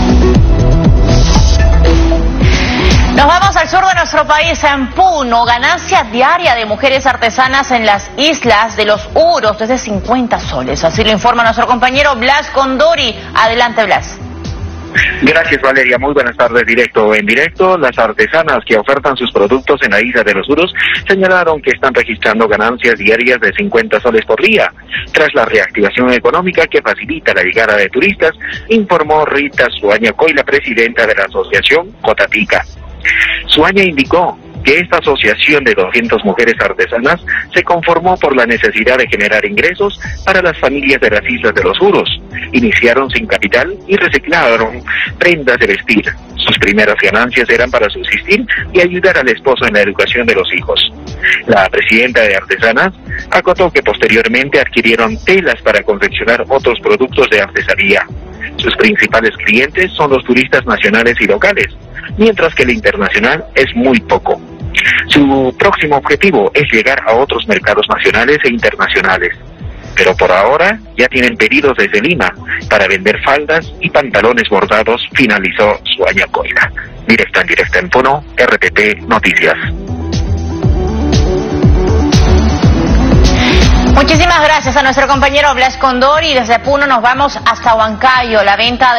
Enlace en vivo. Desde Puno, se informó que ganancia diaria de artesanas de las islas de los Uros es de 50 soles.